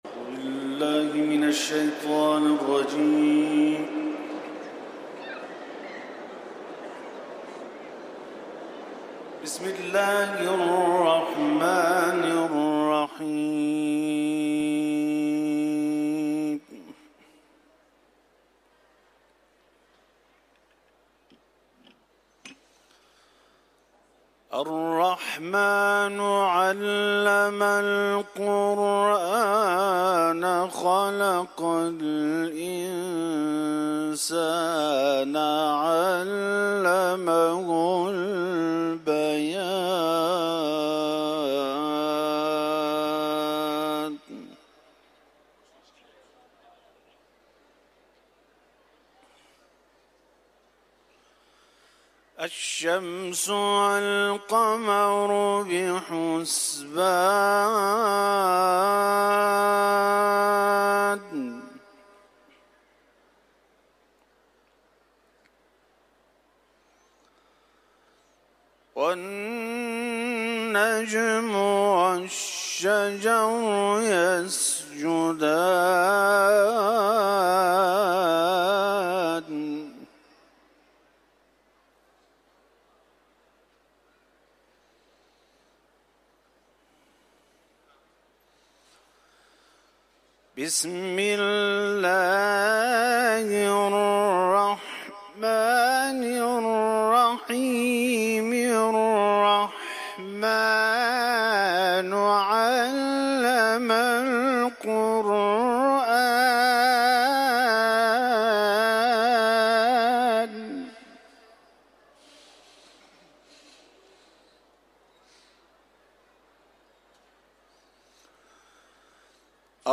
سوره الرحمن ، تلاوت قرآن